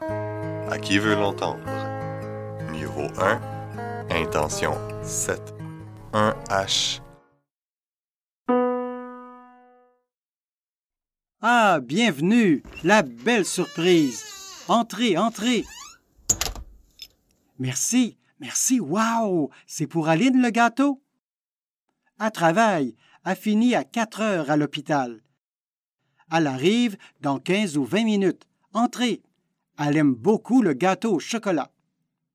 Pronoms personnels conjoints : Associer [a] devant un mot commençant par une consonne (sauf h muet) et [al] devant un mot commençant par une voyelle ou un h muet au pronom sujet elle o